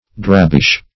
Search Result for " drabbish" : The Collaborative International Dictionary of English v.0.48: Drabbish \Drab"bish\, a. Somewhat drab in color.